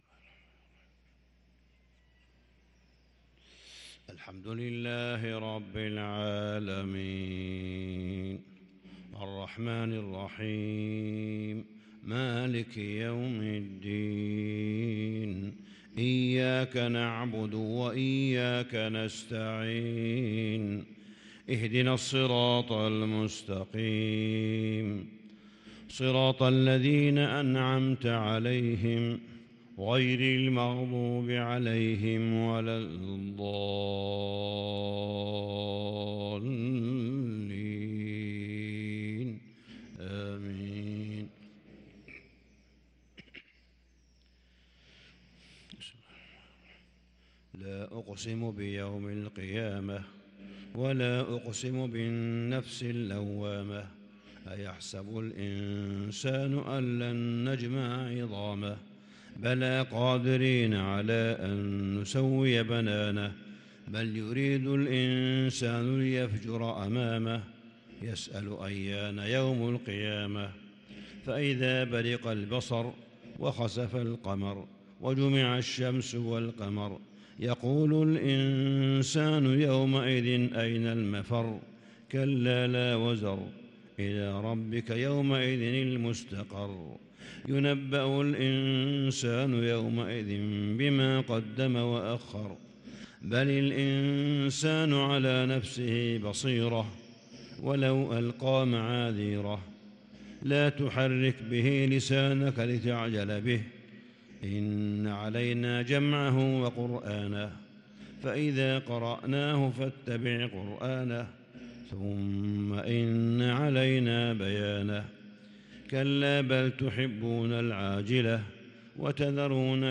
صلاة الفجر للقارئ صالح بن حميد 17 جمادي الأول 1444 هـ
تِلَاوَات الْحَرَمَيْن .